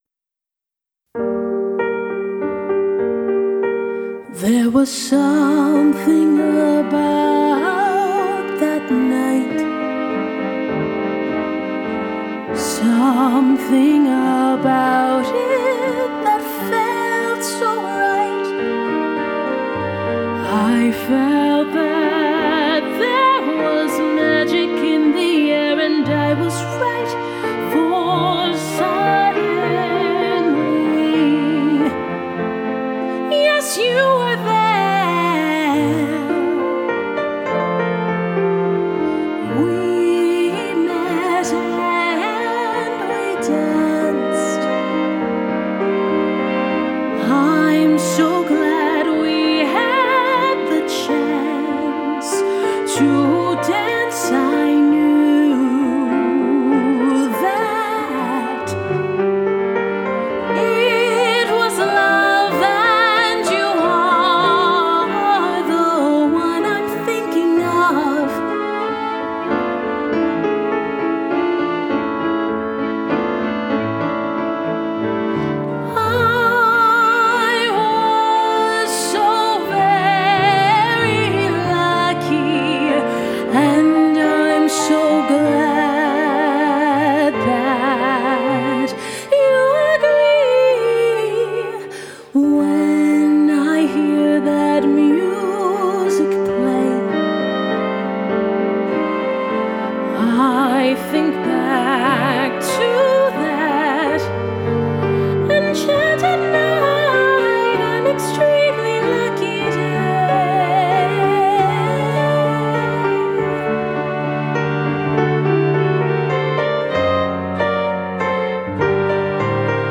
vocalist and world musician